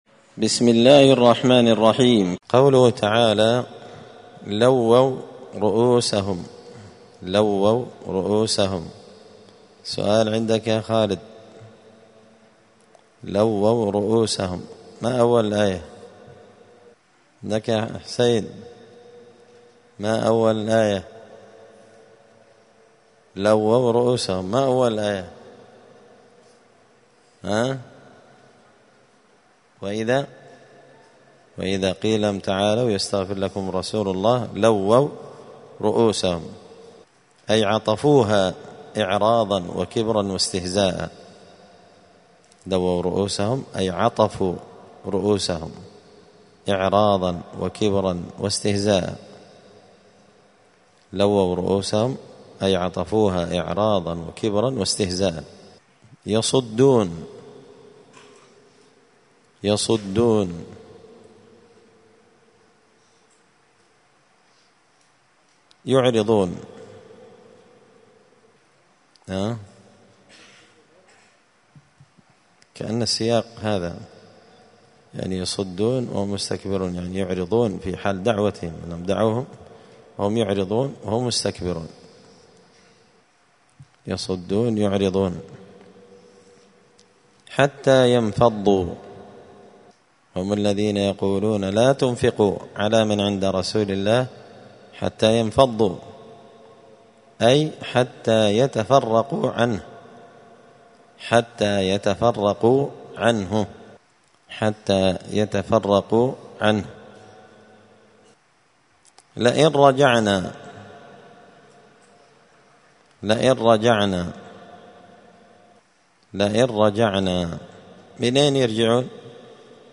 زبدة الأقوال في غريب كلام المتعال الدرس الثامن والأربعون بعد المائة (148)